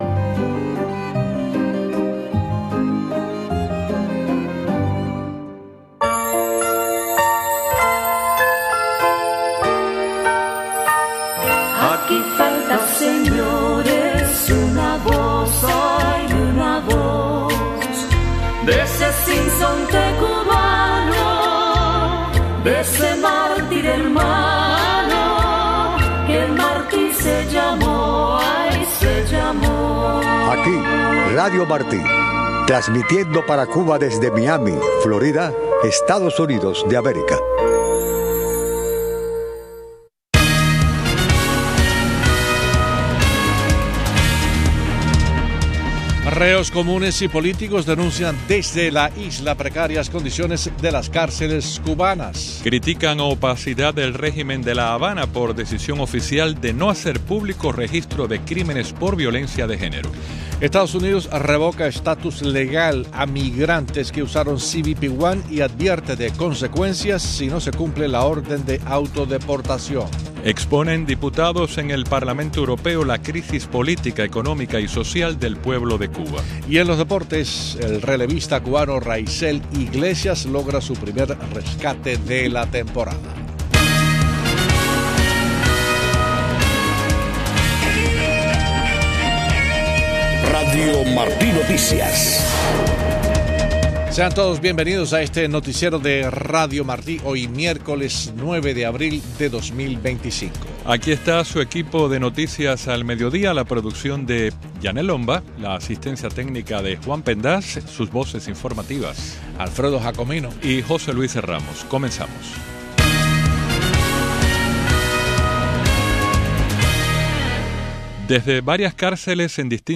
Noticiero de Radio Martí 12:00 PM